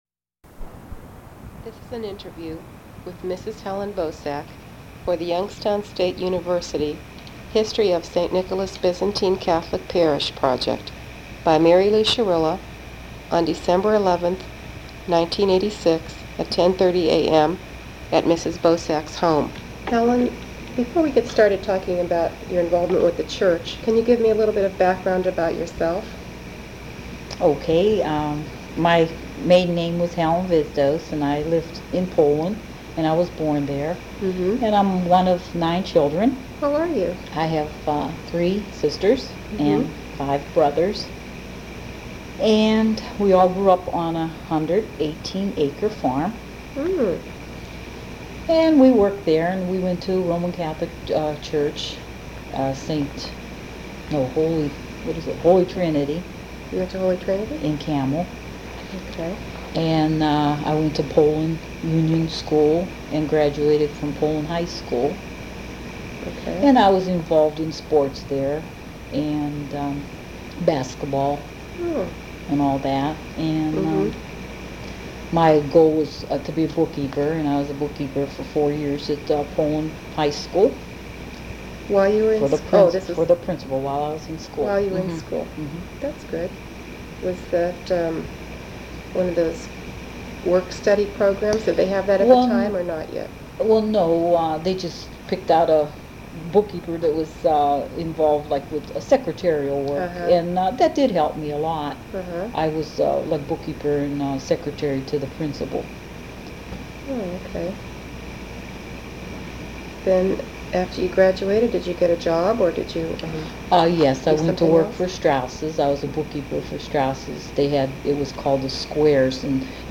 Transcript of interview taped on December 11, 1986.